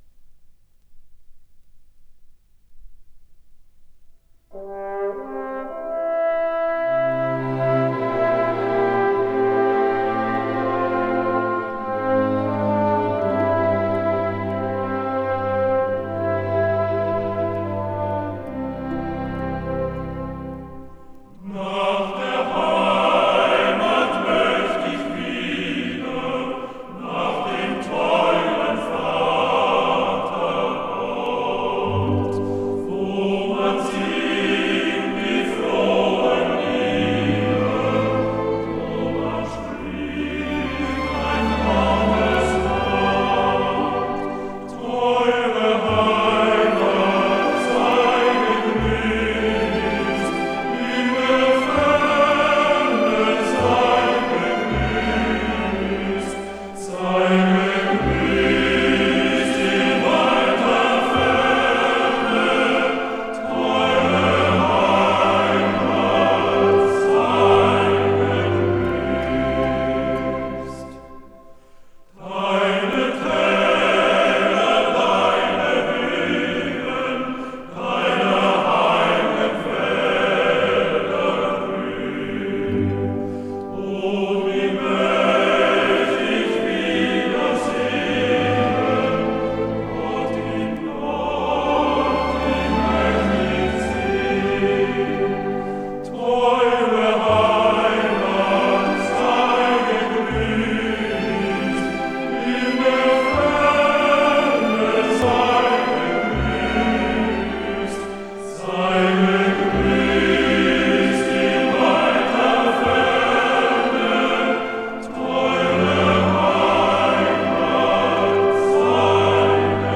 Aufnahme mit 5kB/s, fs=16kHz Hörprobe